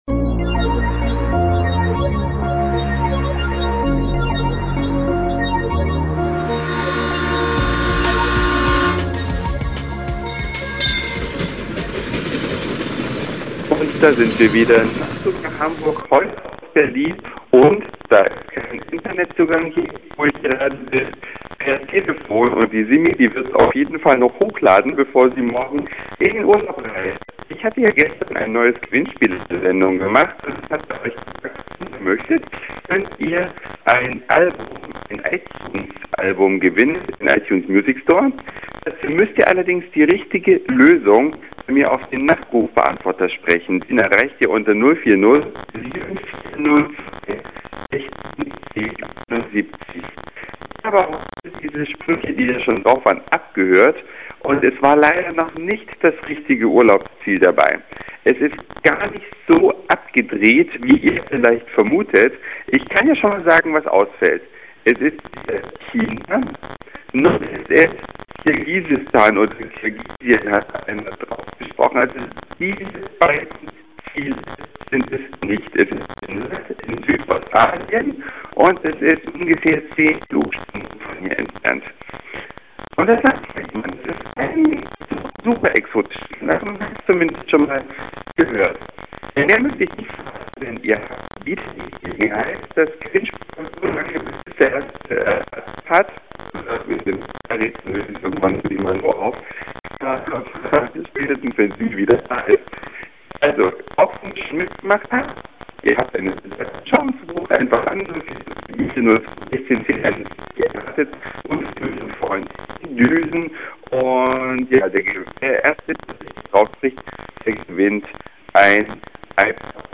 - Podcast kratzt am Anfang leider etwas -